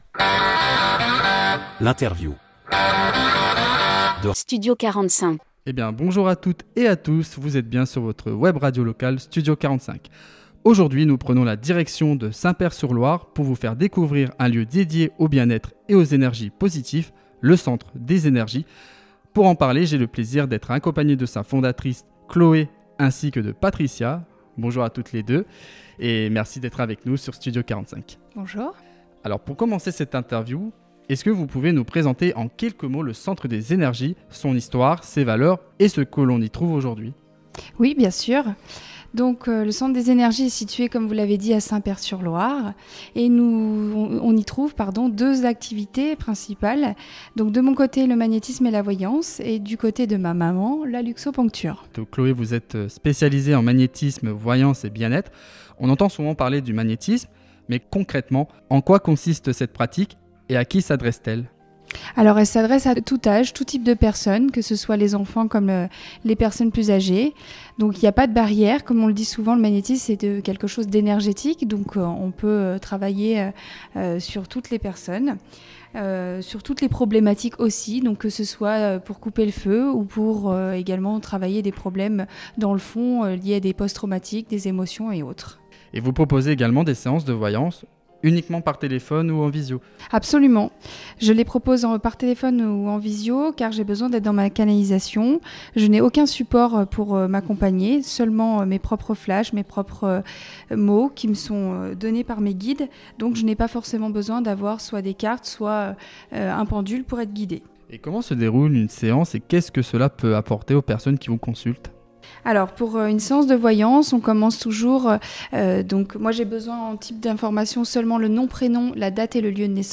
Interview Studio 45 - le centre des énergie